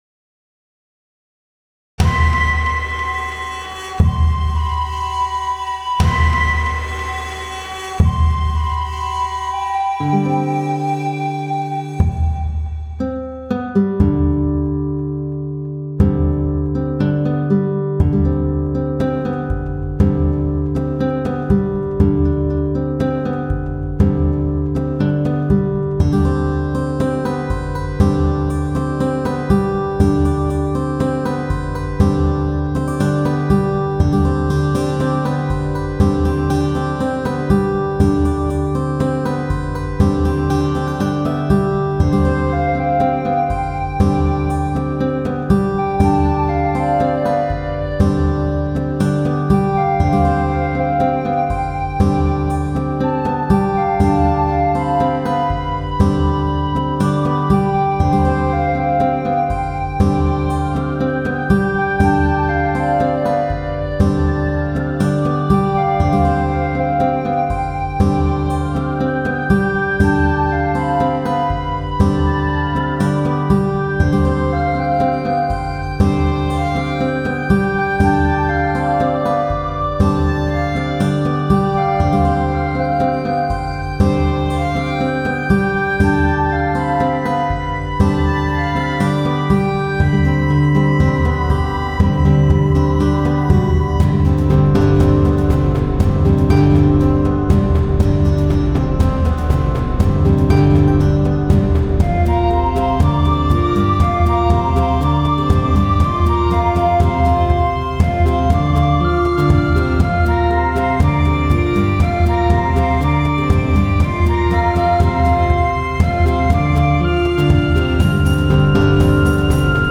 It’s the hymn sung by The Shared Heart.